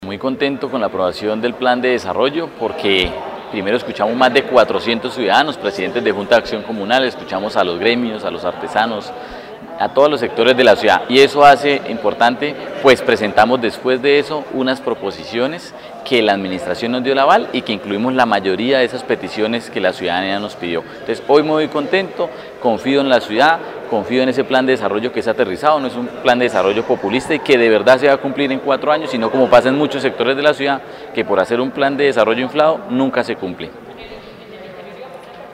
Jorge Humberto Rangel, presidente del Concejo de Bucaramanga